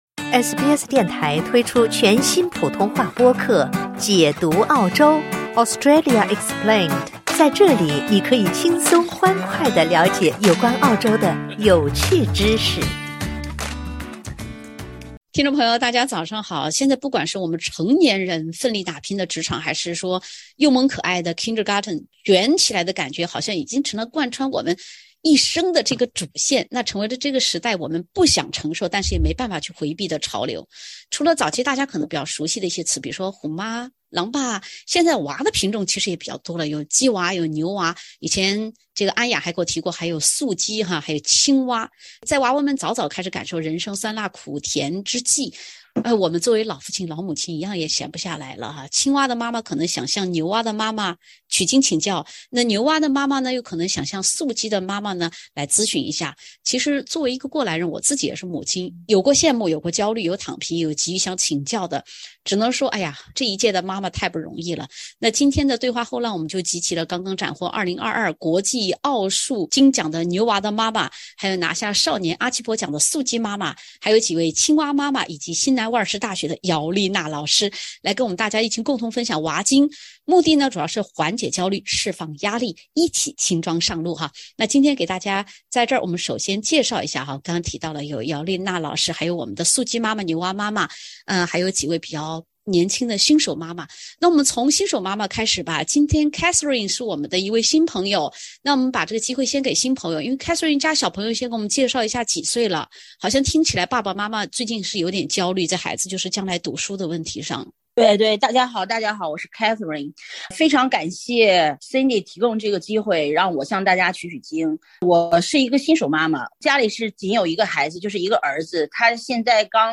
SBS全新谈话类节目《对话后浪》，倾听普通人的烦恼，了解普通人的欢乐，走进普通人的生活。